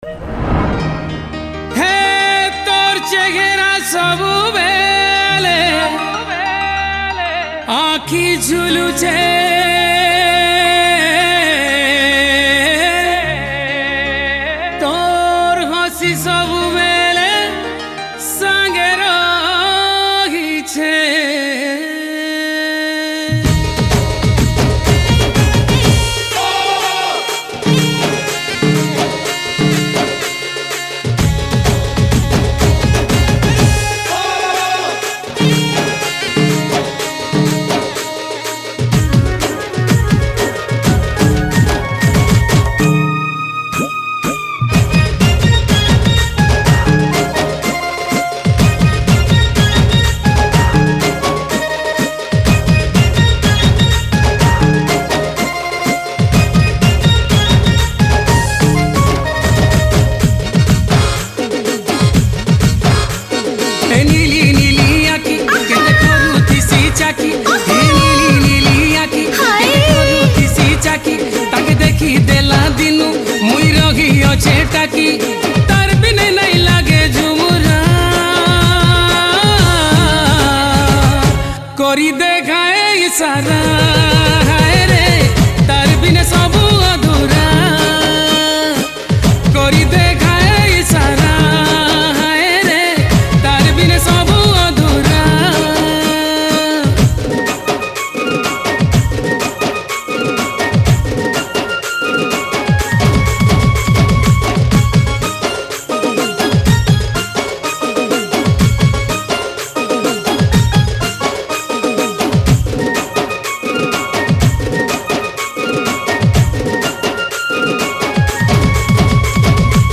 Home  / New Sambalpuri Song 2024